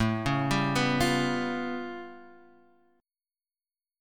A+9 Chord
Listen to A+9 strummed